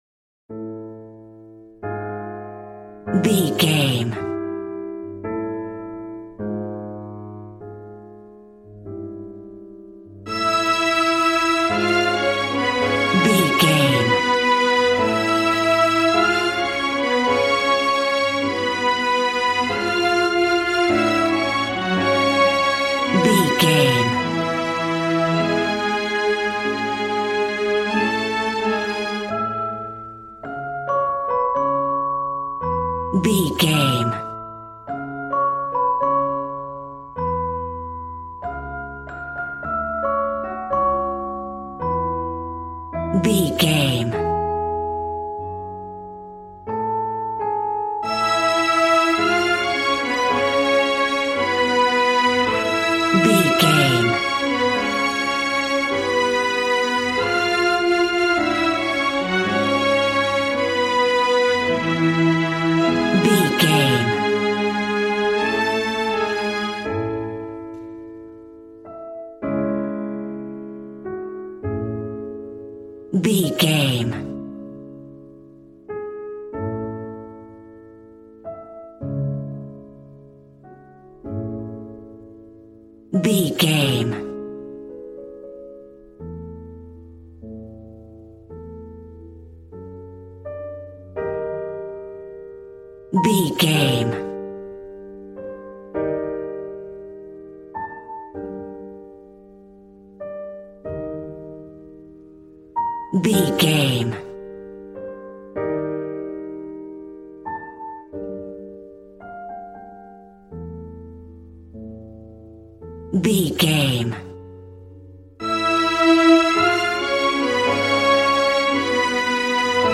Regal and romantic, a classy piece of classical music.
Ionian/Major
regal
strings
violin